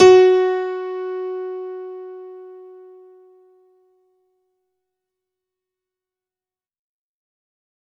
F#3  DANCE-L.wav